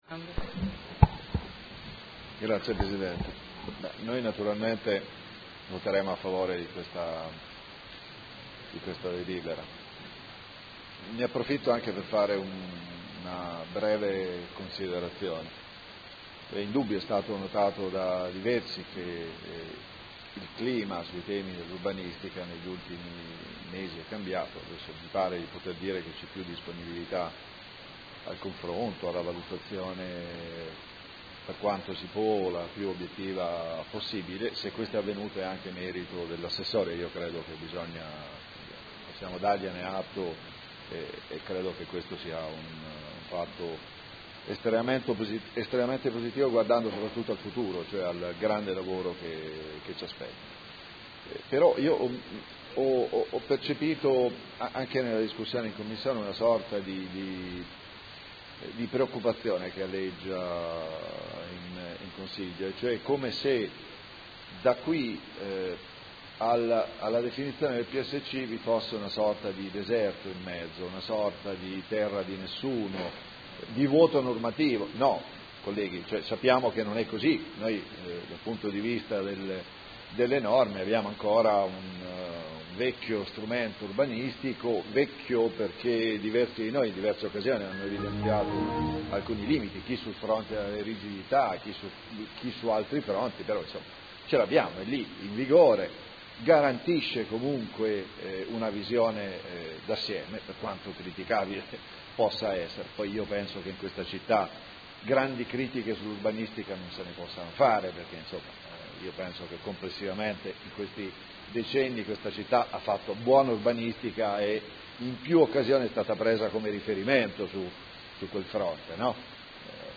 Seduta del 22 ottobre.
Dichiarazione di voto